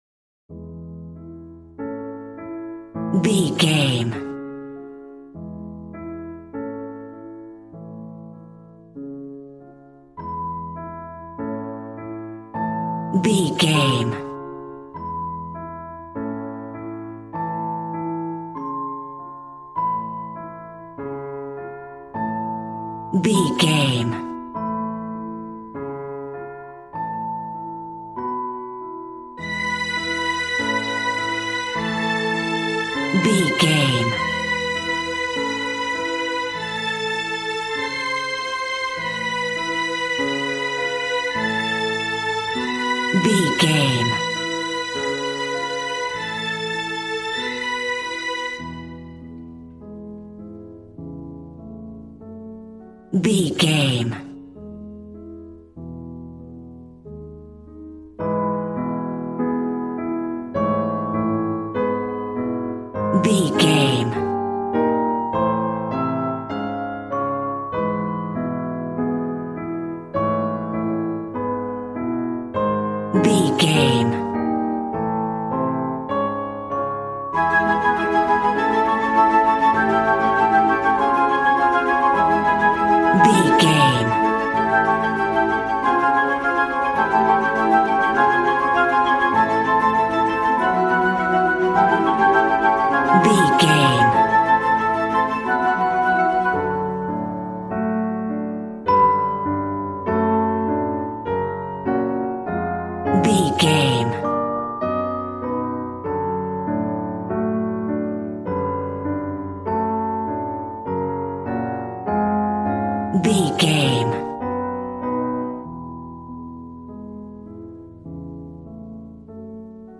Ionian/Major
regal
strings
violin